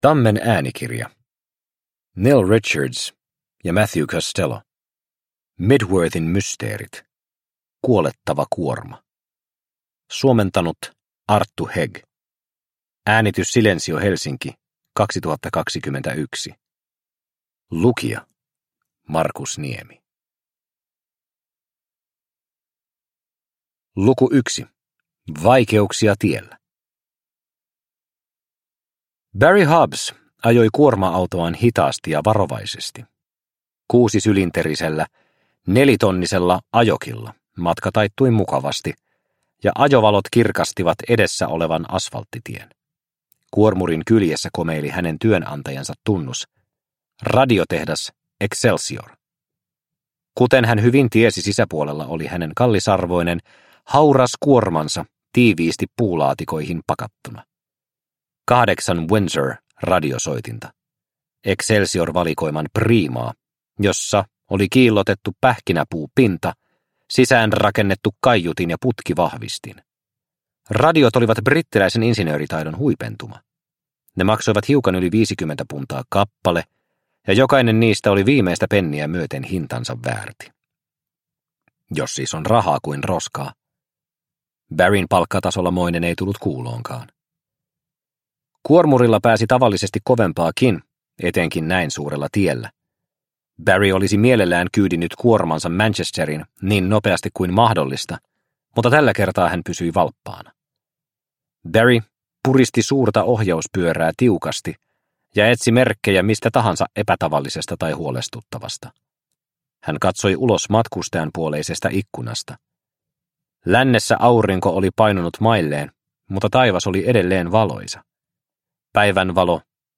Mydworthin mysteerit: Kuolettava kuorma – Ljudbok – Laddas ner